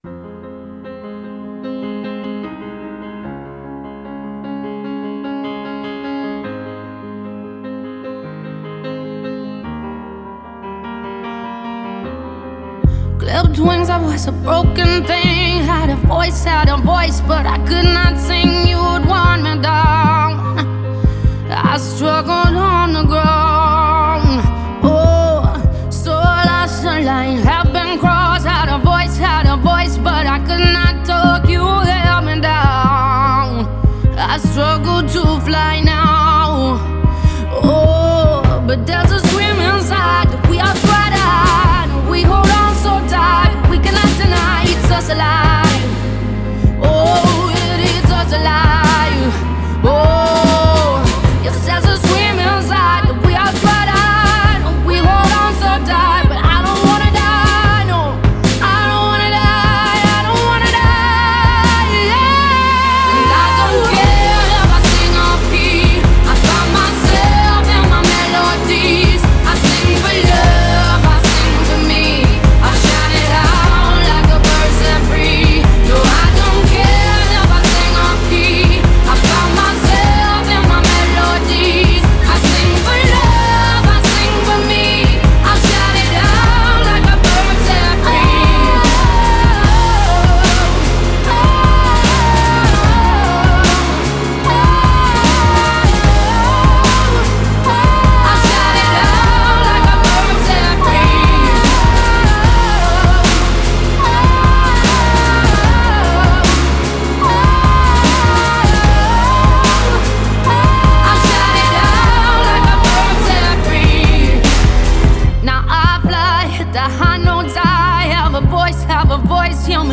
медленная музыка , красивые песни
спокойная музыка